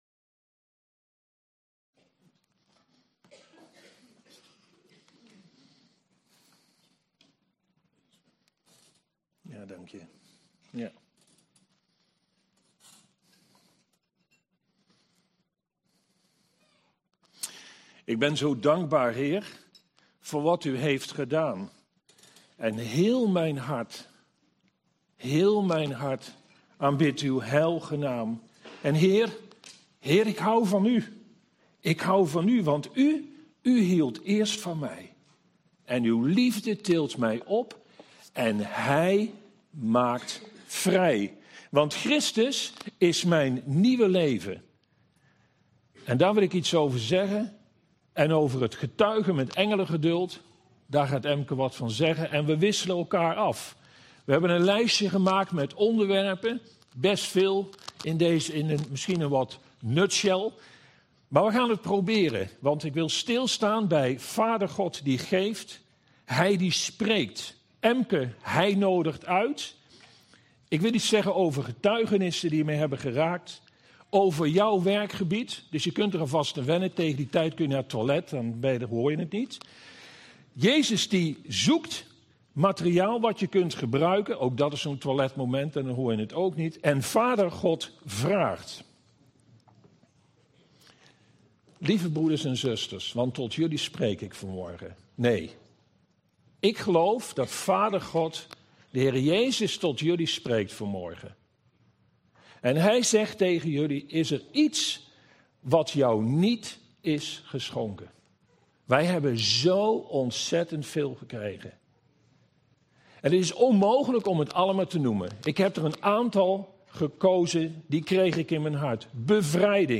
Toespraak 17 november: Christus mijn leven en getuigen - De Bron Eindhoven